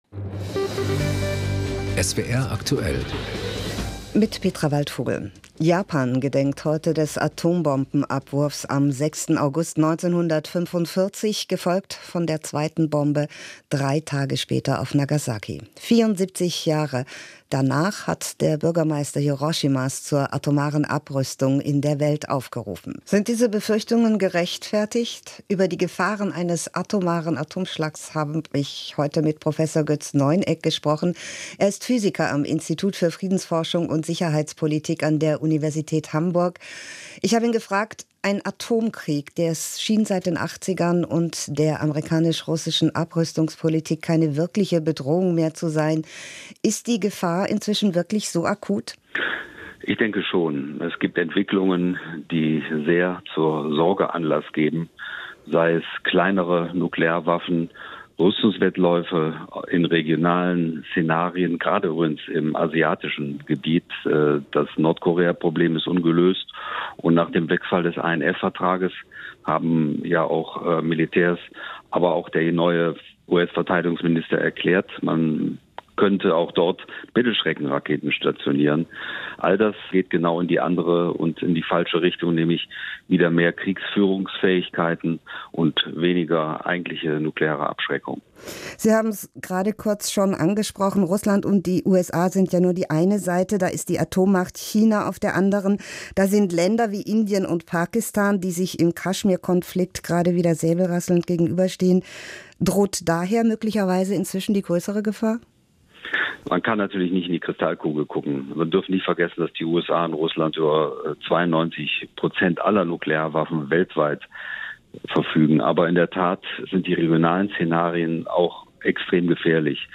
Jahrestag des Atombombenabwurfs auf Hiroshima - Interviews im SWR und WDR